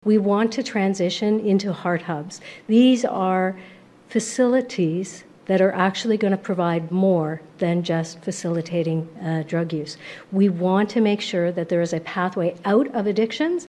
While speaking about the report, Health Minister Sylvia Jones once again focused on the planned homelessness and addiction recovery treatment hubs.
jones-response-1.mp3